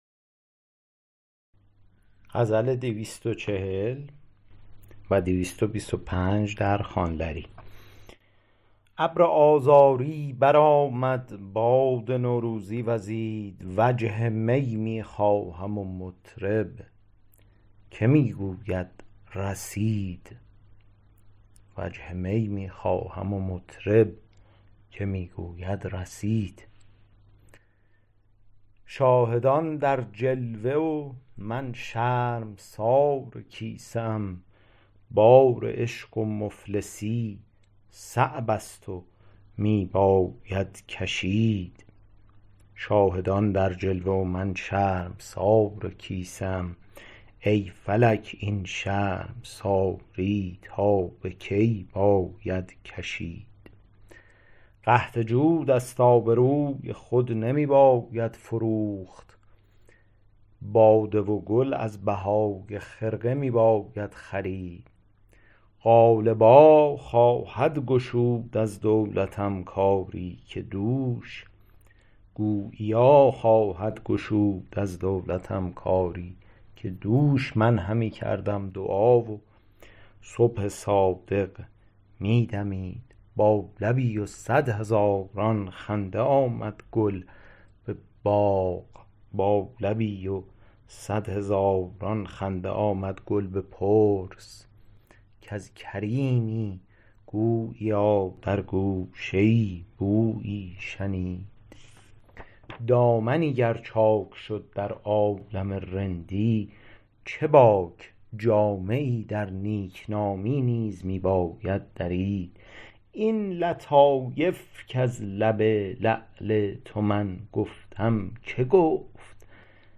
شرح صوتی غزل شمارهٔ ۲۴۰